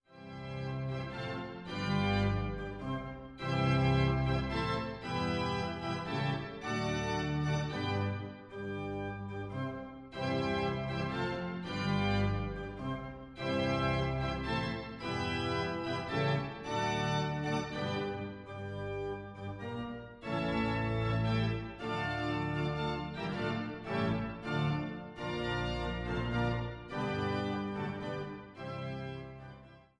7 Aus "Skizzen für Pedalflügel" op. 58 - Nr. II, Nicht schnell und sehr markiert